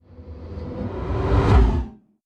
Vacuum Swoosh Transition - Botón de Efecto Sonoro